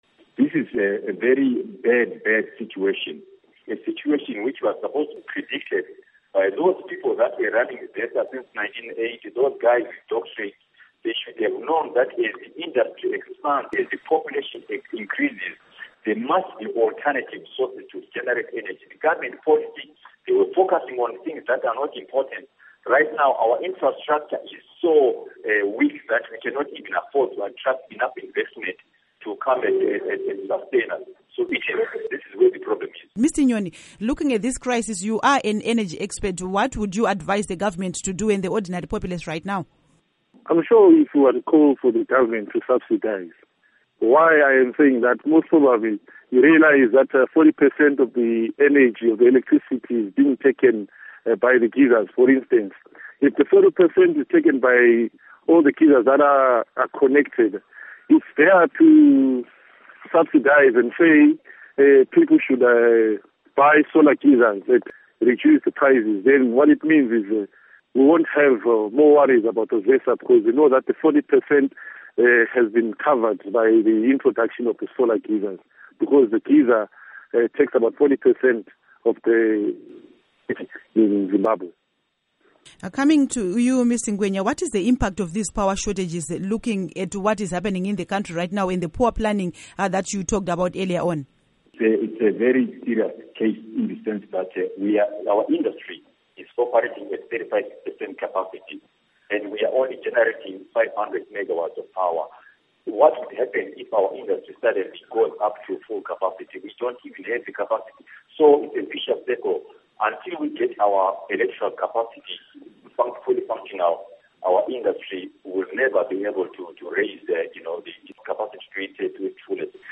Panel Interview on Electricity